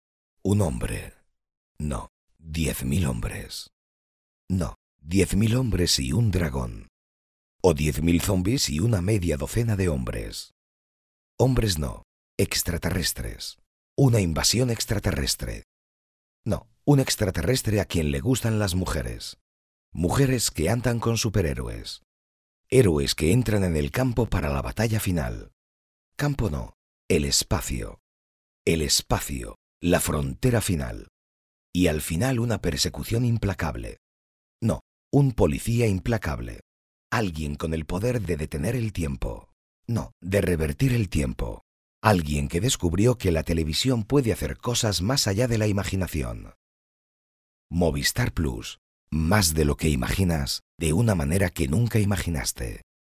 kastilisch
Sprechprobe: Werbung (Muttersprache):
An authentic, powerful and true Spanish voice. A deep voice, a temperate drama.